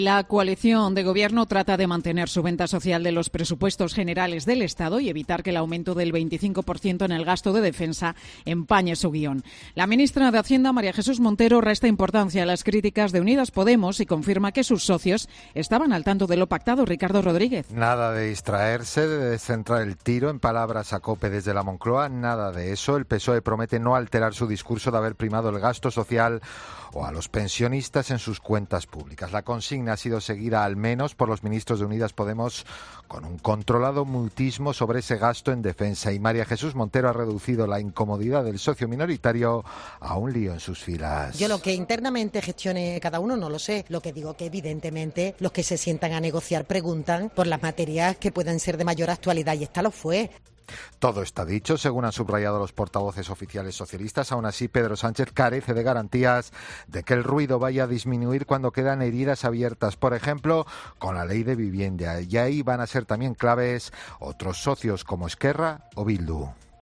La ministra de Hacienda resta importancia a las críticas de Podemos a los PGE. Crónica